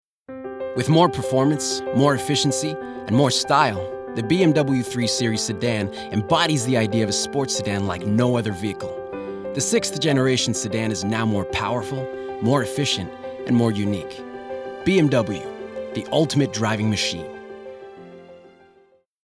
Adult
standard us | natural